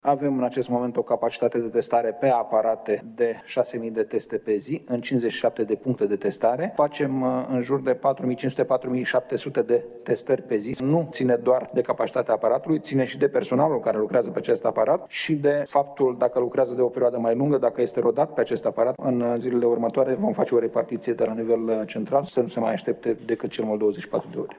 Ministrul Sănătăţii, Nelu Tătaru spunea că, în prezent, s-ar putea face chiar mai multe teste, însă o problemă este lipsa personalului care să aibă pregătirea necesară sau experienţa suficientă pentru a se atinge capacitatea maximă existentă: